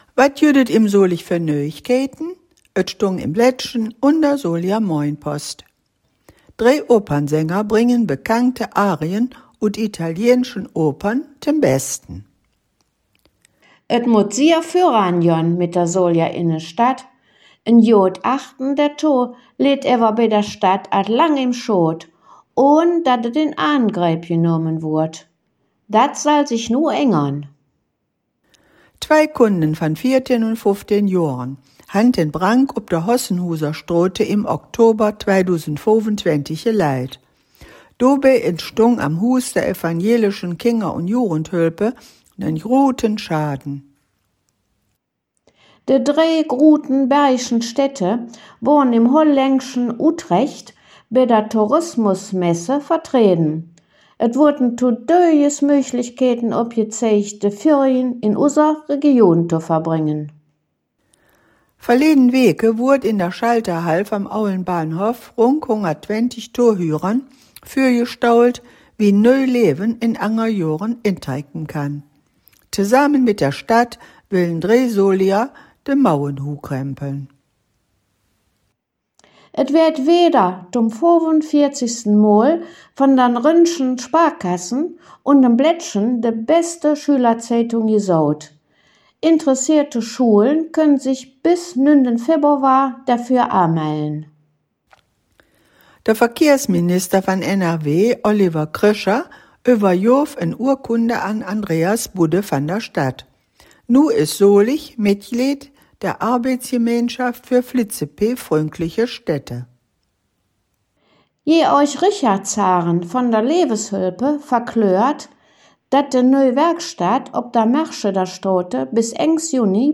Der in Solingen gesprochene Dialekt wird Solinger Platt genannt. Er zählt zu den bergischen Mundarten und wird dem südniederfränkische Dialektraum zugeordnet.
Zugleich wird im Solinger Platt aber auch die Nähe zum ripuarischen Sprachraum (vor allem durch das Kölsch bekannt) hörbar.